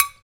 Index of /90_sSampleCDs/Roland LCDP14 Africa VOL-2/PRC_Afro Toys/PRC_Afro Metals
PRC METAL 2C.wav